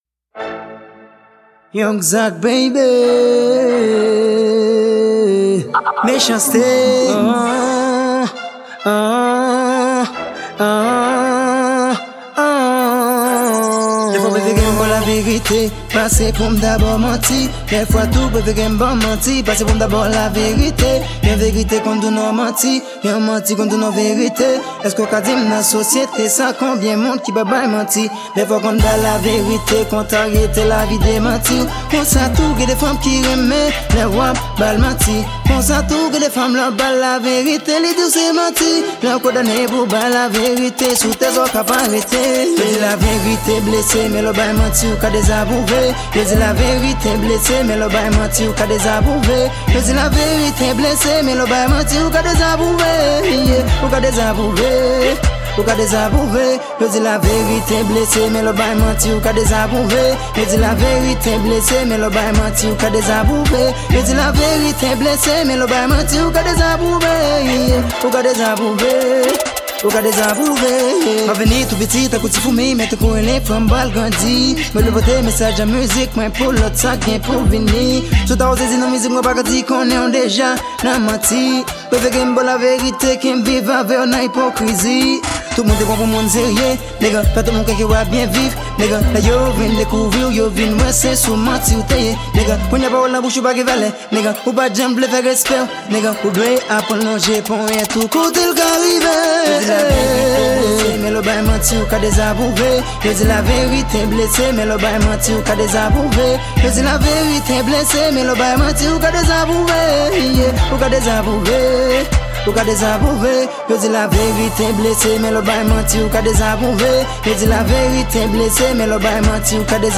Genre: R&B .